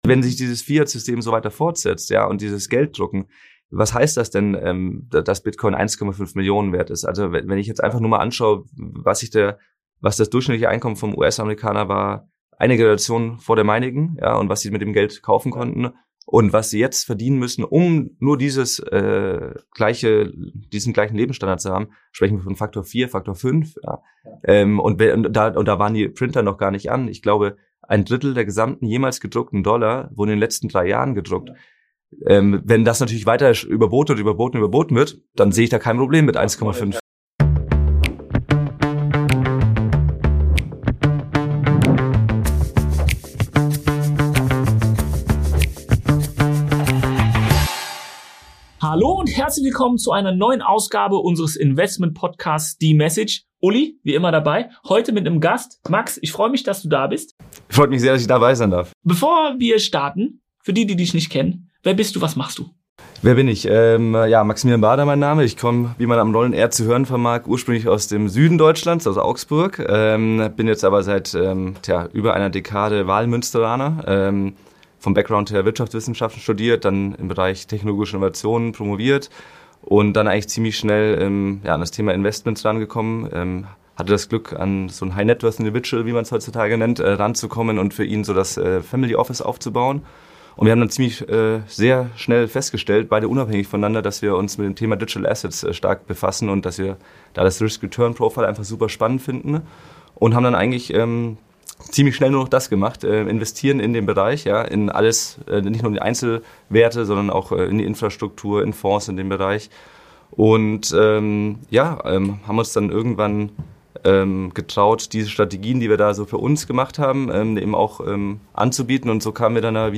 Ein Gespräch über Chancen, Risiken und die Zukunft digitaler Assets.